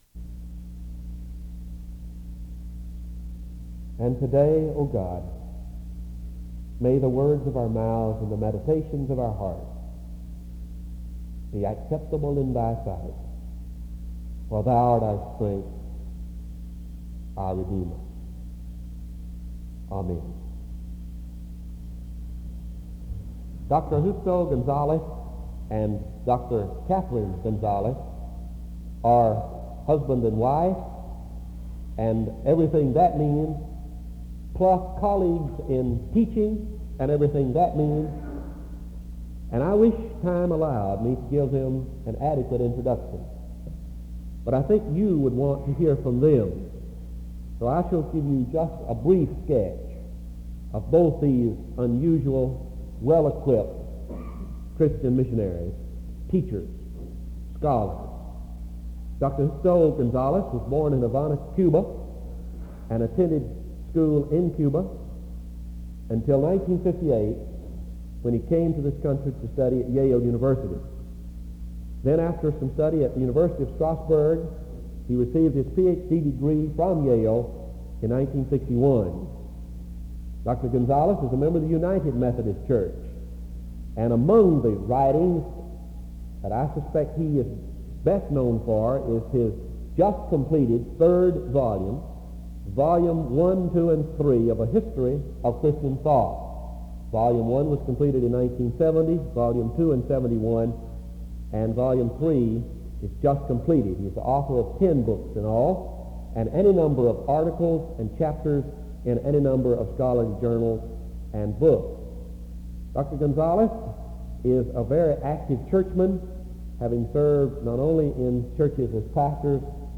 Both professors came to SEBTS to speak about the history and necessity of missions. There is an opening prayer from 0:00-0:21. The two speakers are introduced from 0:23-4:41. Both of them share the stage as they go back and forth sharing personal stories and New Testament examples of sharing the gospel to those who need it most.
A closing prayer is offered from 43:51-44:08.